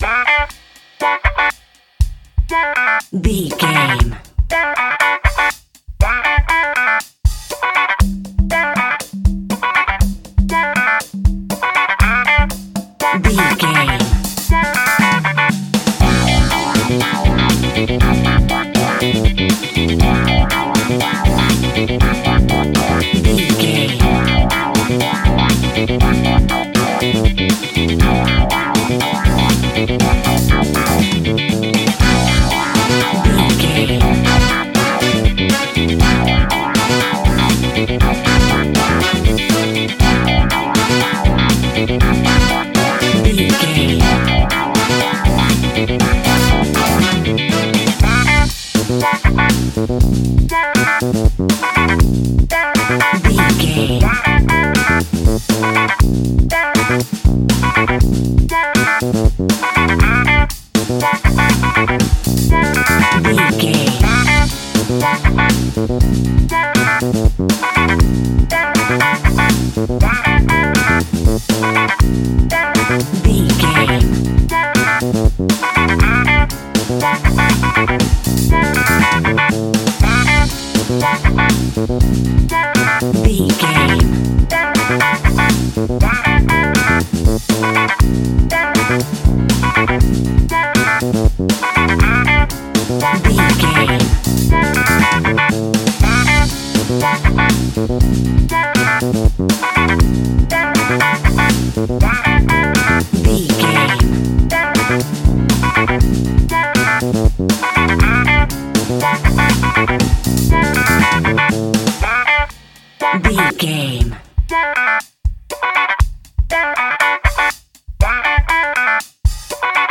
Aeolian/Minor
groovy
uplifting
bouncy
smooth
drums
electric guitar
bass guitar
horns
funky house
disco house
electronic funk
upbeat
synth leads
Synth pads
synth bass
drum machines